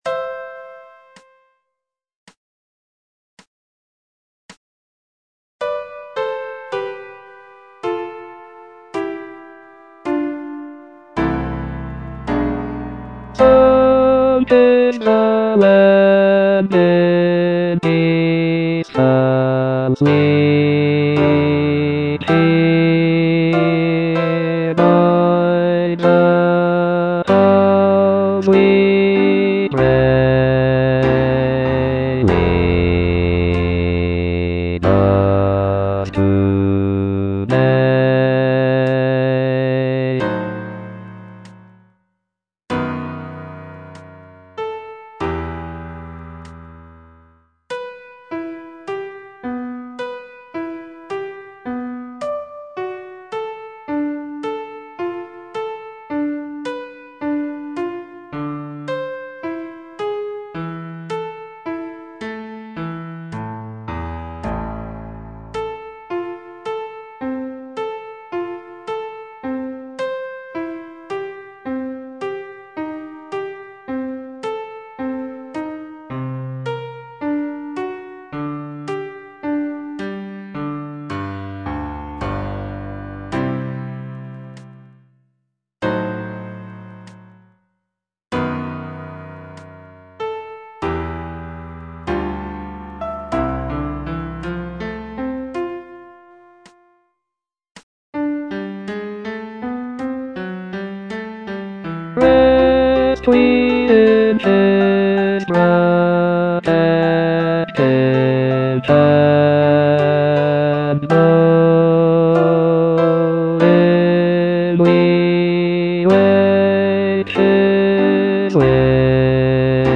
E. ELGAR - FROM THE BAVARIAN HIGHLANDS Aspiration (bass II) (Voice with metronome) Ads stop: auto-stop